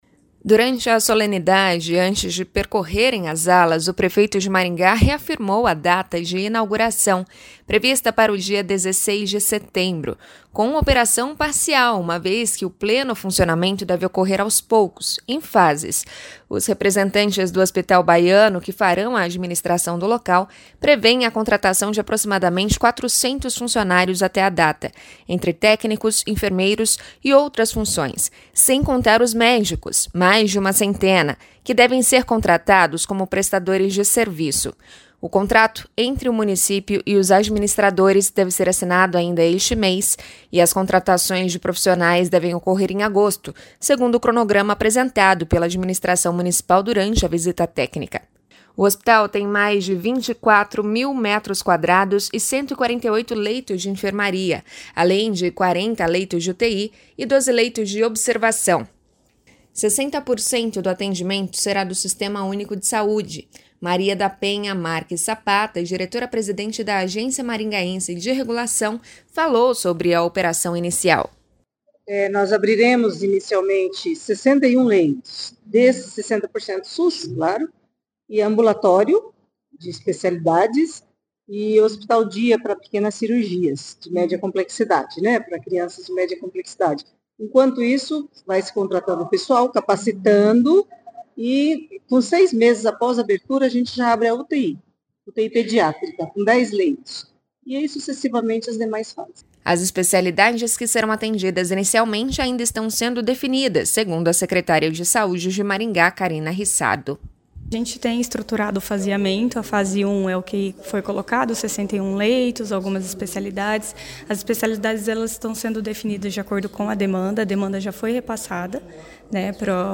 Maria da Penha Marques Sapata, diretora-presidente da Agência Maringaense de Regulação, falou sobre a operação inicial.
As especialidades que serão atendidas inicialmente ainda estão sendo definidas, segundo a secretária de saúde de Maringá, Karina Rissardo.